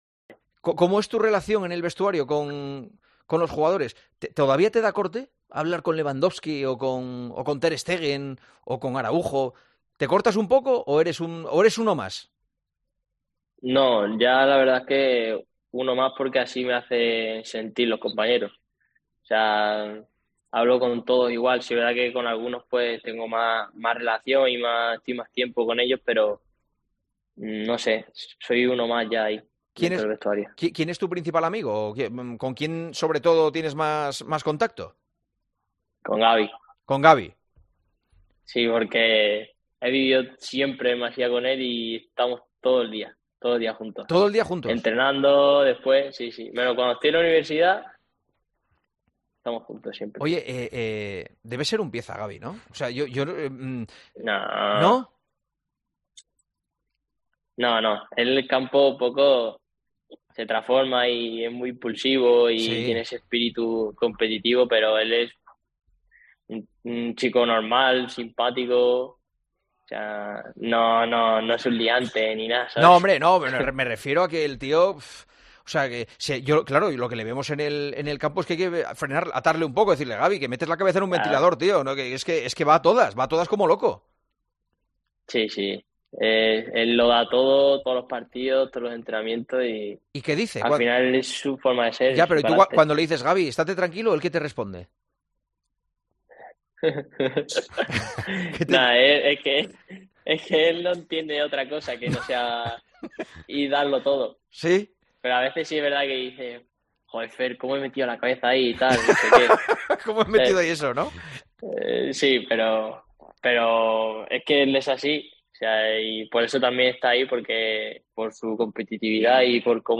AUDIO: Fermín López ha pasado este miércoles por los micrófonos de El Partidazo de COPE y ha repasado buen estado de forma con Juanma Castaño.